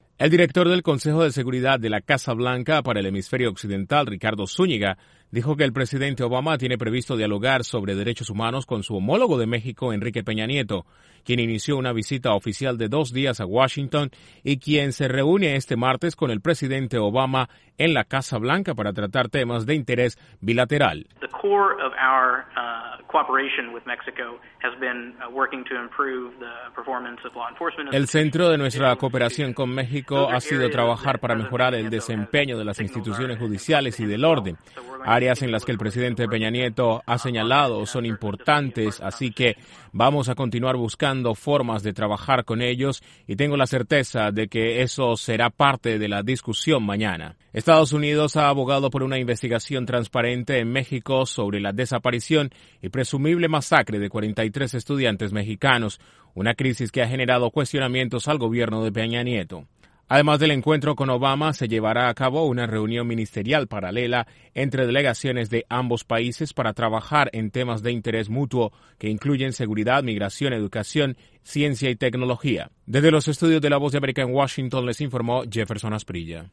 El presidente Obama recibe este martes en la Casa Blanca a su homólogo de México Enrique Peña Nieto con quien dialogará sobre derechos humanos entre otros temas. Desde la Voz de América en Washington informa